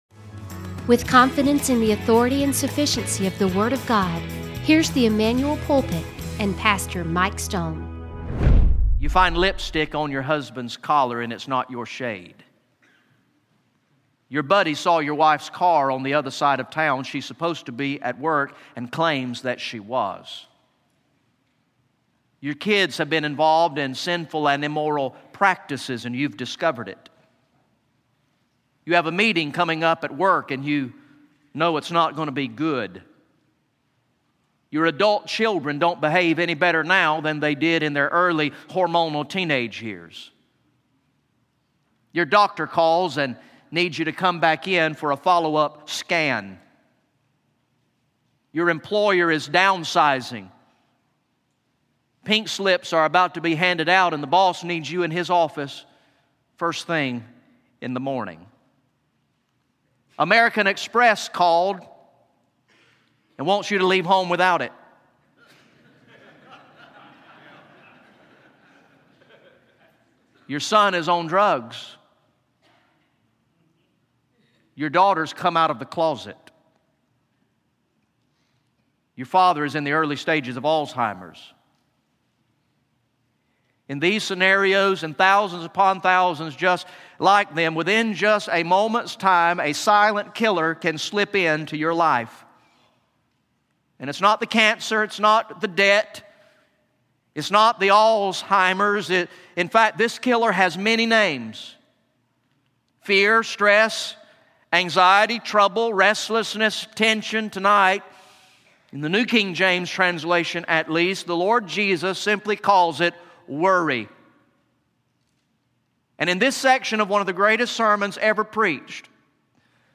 Recorded in the evening worship service on Sunday, February 23, 2020